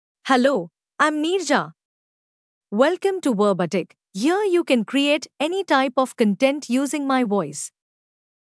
FemaleEnglish (India)
Neerja is a female AI voice for English (India).
Voice sample
Neerja delivers clear pronunciation with authentic India English intonation, making your content sound professionally produced.